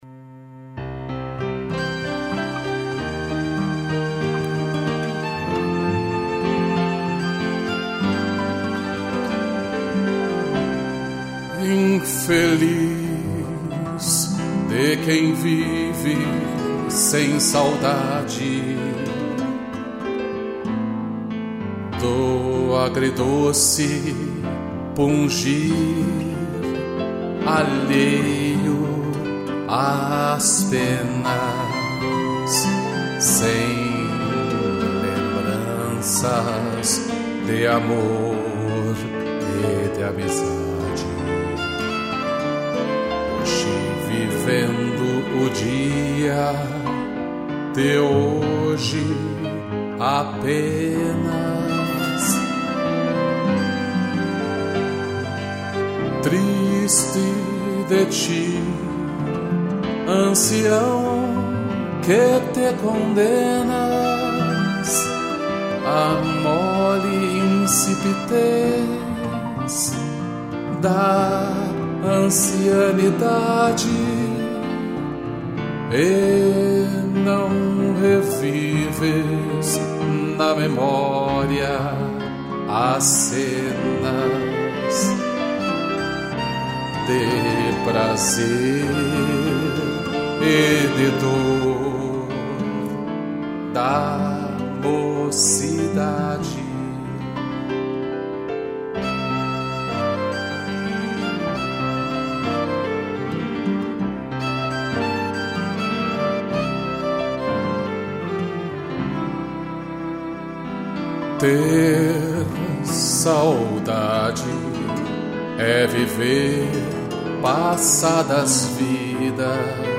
voz e violão
2 pianos, violino e cello